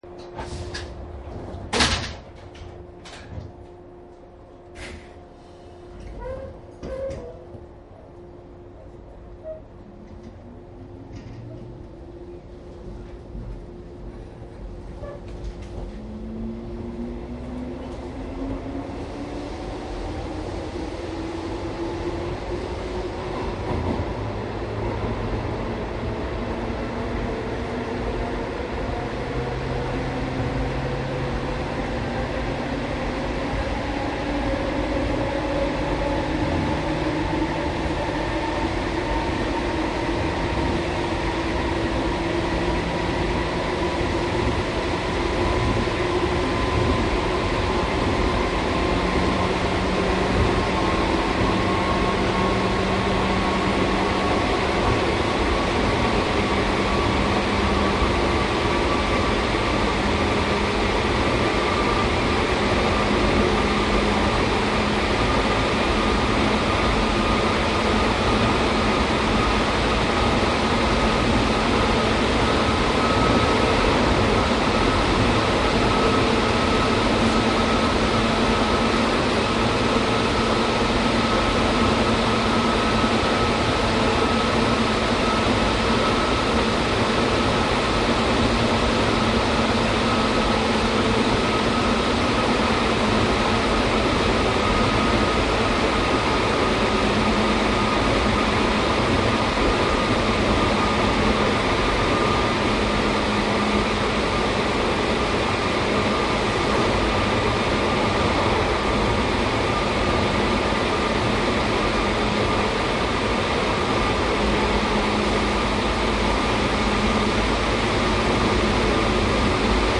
JR常磐線快速・武蔵野線 103系走行音♪
■府中本町→東所沢モハ103－261（MT55後期）
マスター音源はデジタル44.1kHz16ビット（マイクＥＣＭ959）で、これを編集ソフトでＣＤに焼いたものです。